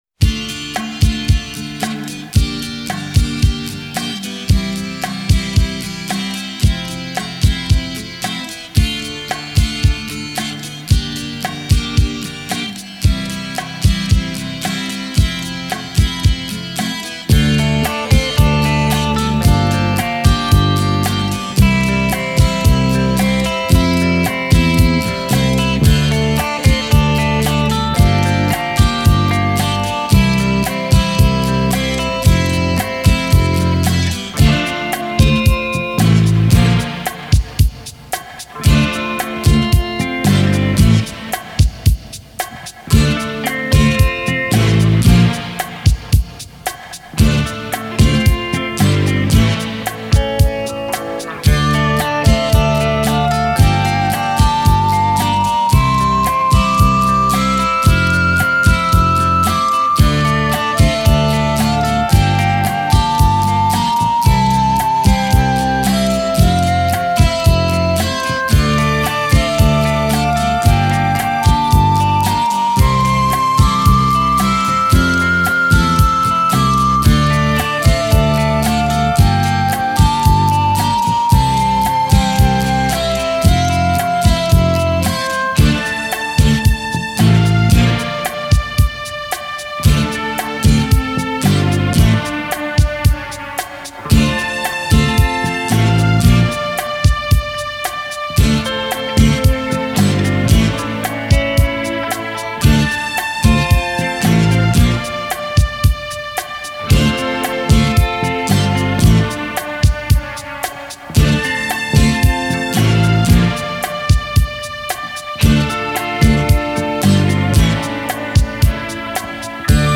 Genre: Rock